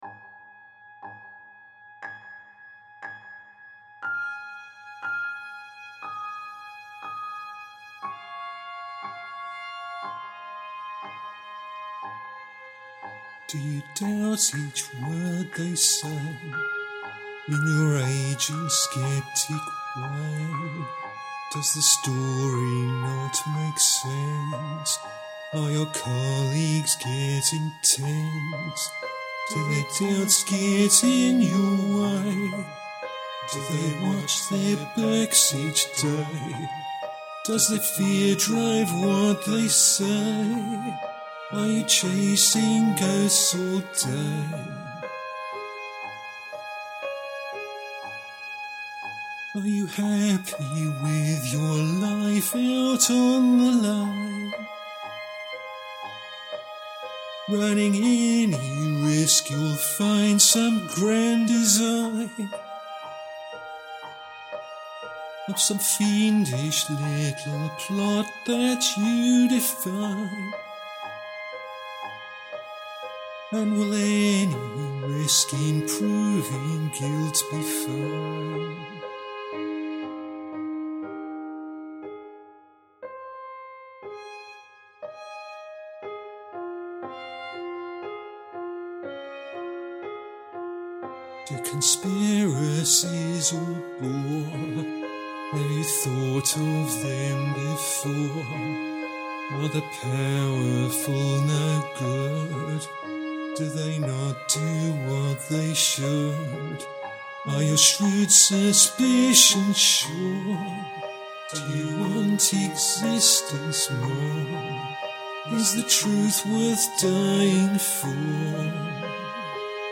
Vocals recorded 19 October 2019.